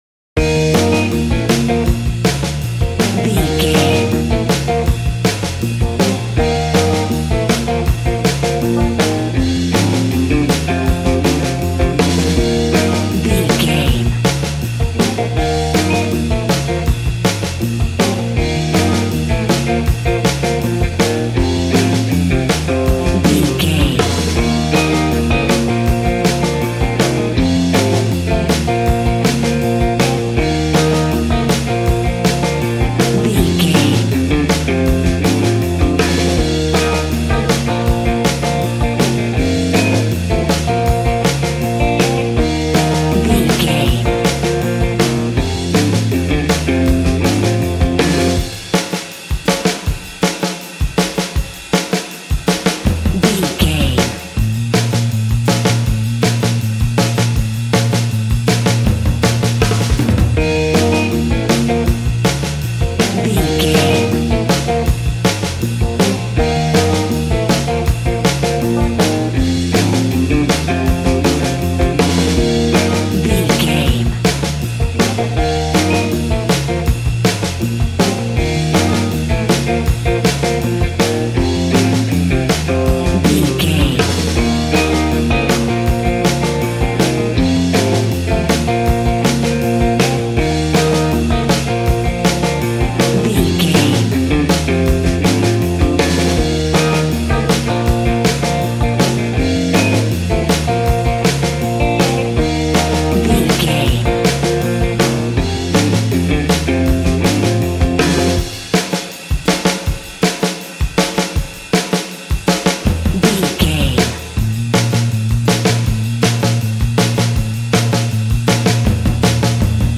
Ionian/Major
B♭
cheerful/happy
double bass
drums
piano